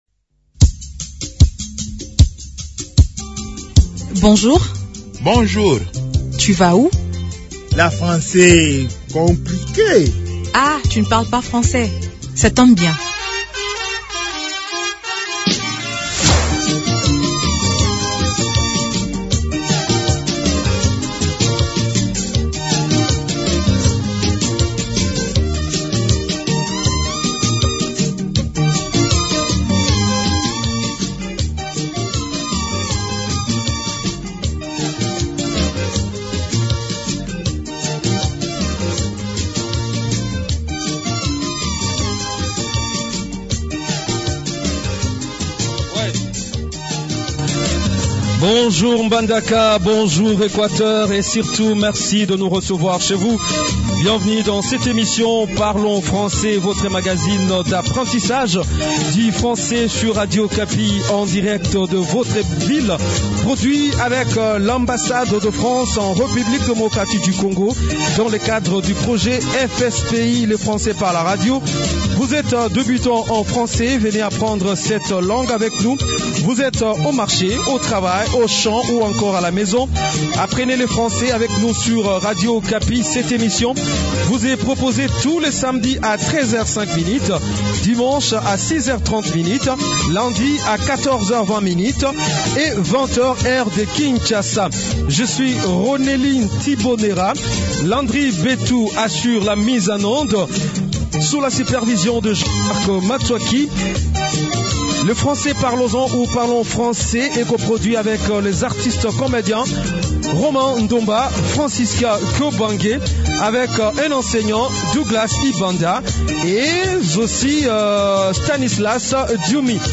Dans cet épisode de Parlons Français, produit dans la ville de Mbandaka, nous découvrons des expressions simples et pratiques pour dire son nom en français. Les apprenants, venus de différents quartiers, partagent leur enthousiasme d'apprendre le français dans une ambiance détendue, où la bonne humeur et la convivialité se rencontrent.